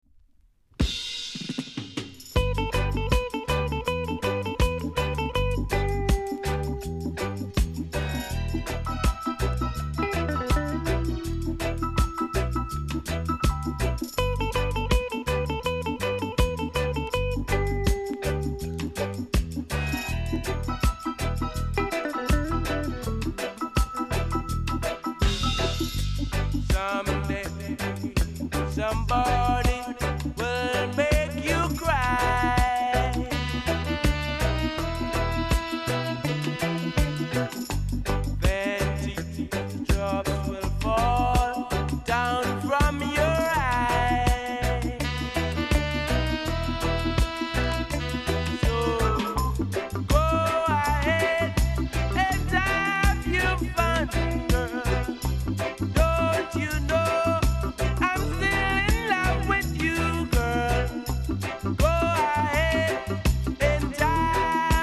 ※多少小さなノイズはありますが概ね良好です。
サイドB VERSION/DUB